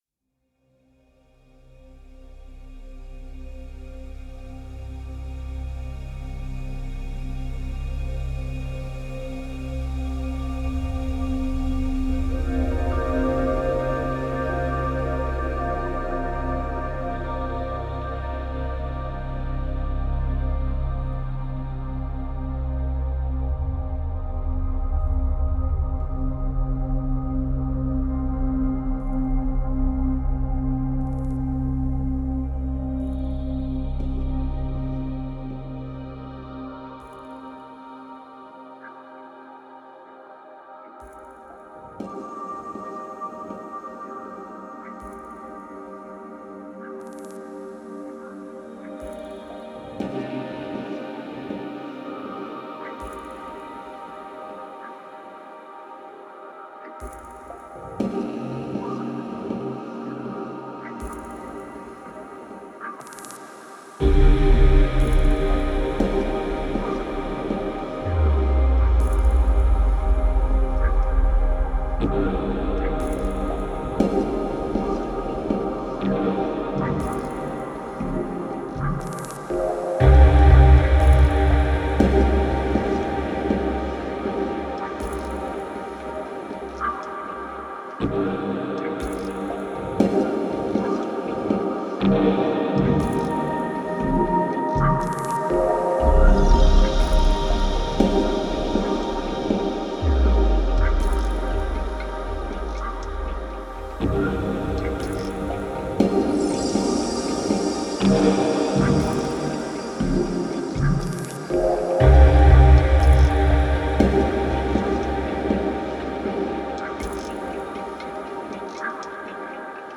Genre: Downtempo, Chillout.